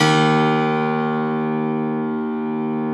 53e-pno03-D0.wav